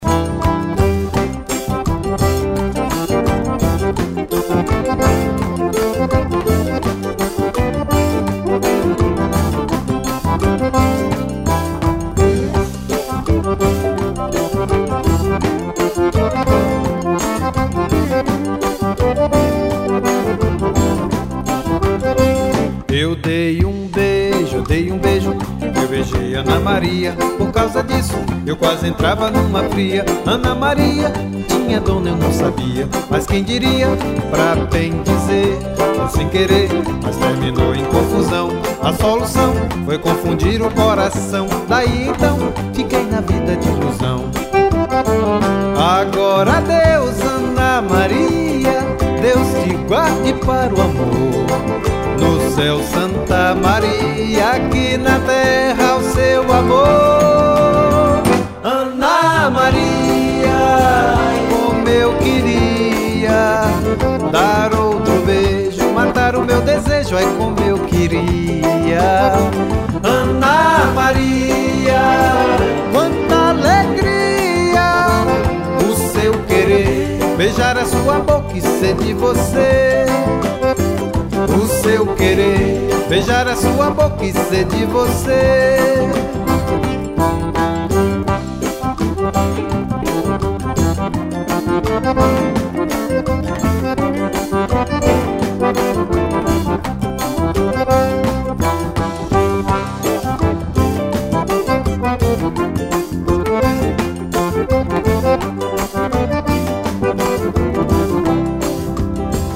1083   01:49:00   Faixa:     Forró